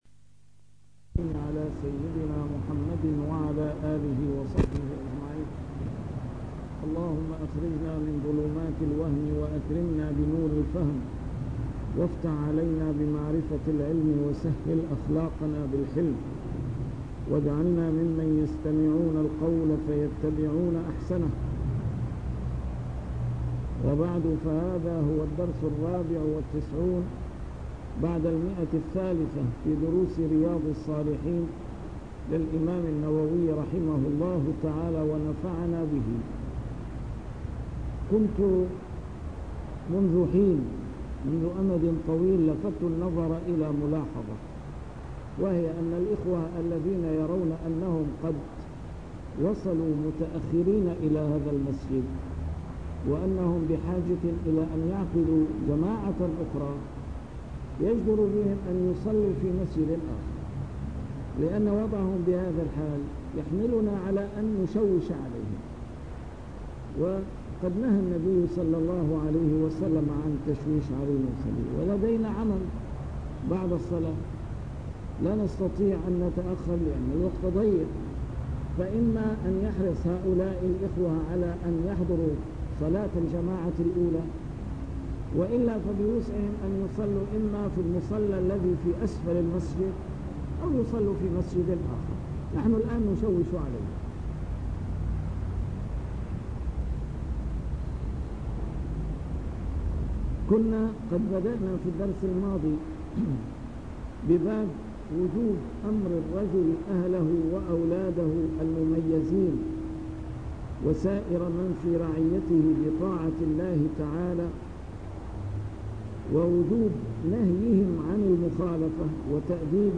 A MARTYR SCHOLAR: IMAM MUHAMMAD SAEED RAMADAN AL-BOUTI - الدروس العلمية - شرح كتاب رياض الصالحين - 394- شرح رياض الصالحين: أمر الرجل أهله بطاعة الله